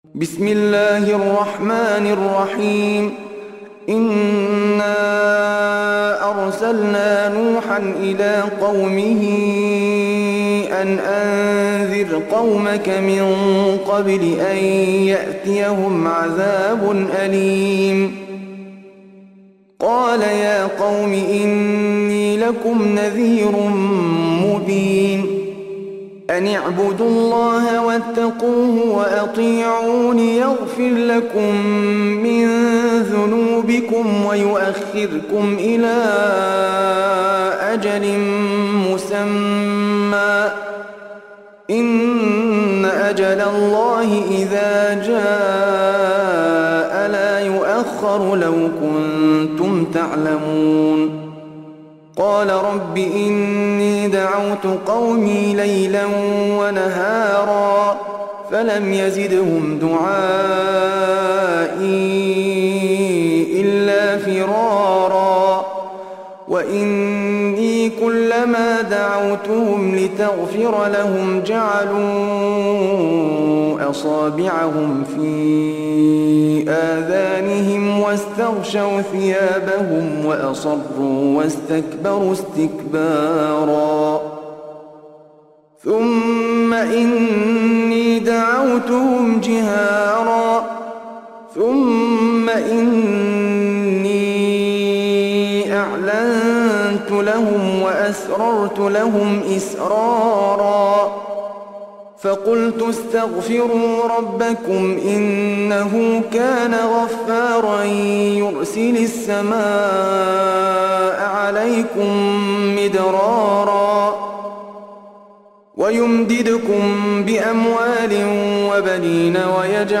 Surah Sequence تتابع السورة Download Surah حمّل السورة Reciting Murattalah Audio for 71. Surah N�h سورة نوح N.B *Surah Includes Al-Basmalah Reciters Sequents تتابع التلاوات Reciters Repeats تكرار التلاوات